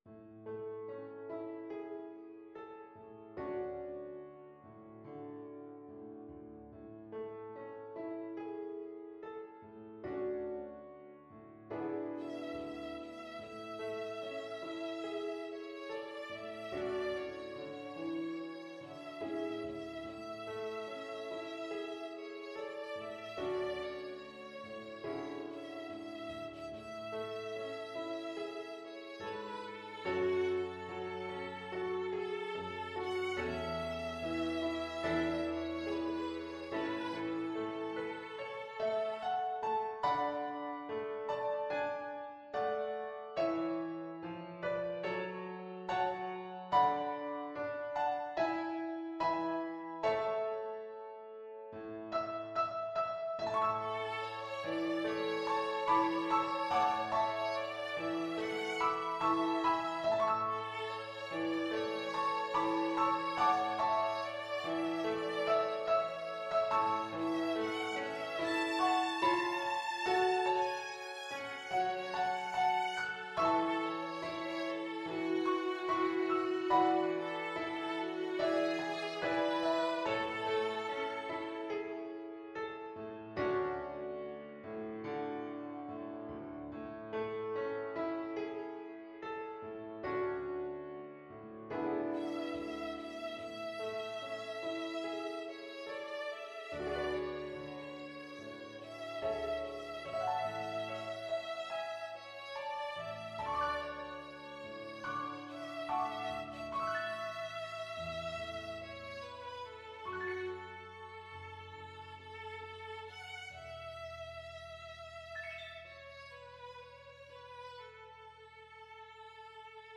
Violin
A major (Sounding Pitch) (View more A major Music for Violin )
4/4 (View more 4/4 Music)
Molto espressivo =c.72
Classical (View more Classical Violin Music)
Welsh